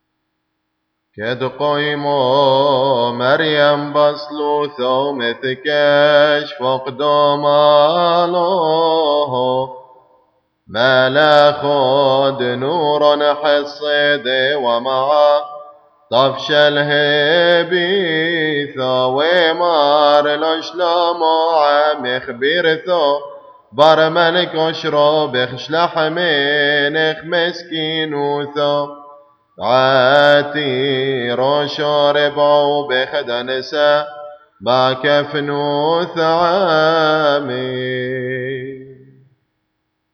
After Gospel Hymn